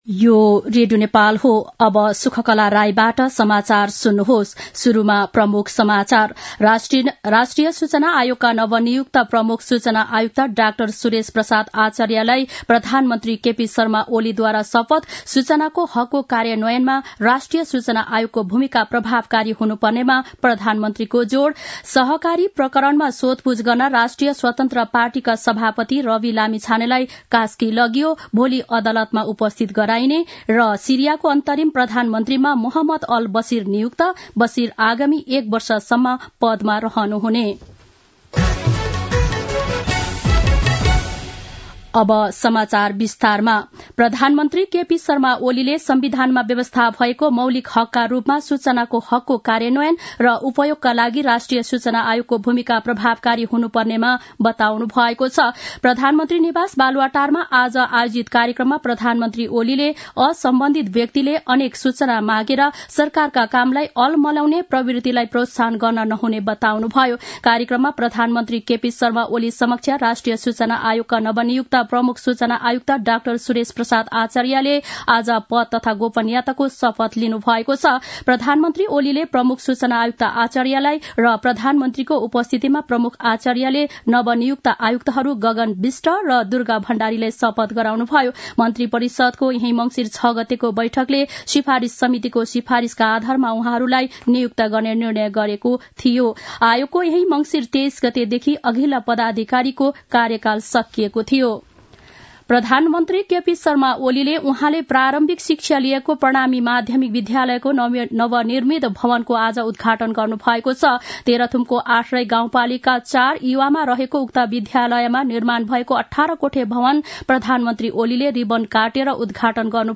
दिउँसो ३ बजेको नेपाली समाचार : २७ मंसिर , २०८१
3-pm-nepali-news-1-7.mp3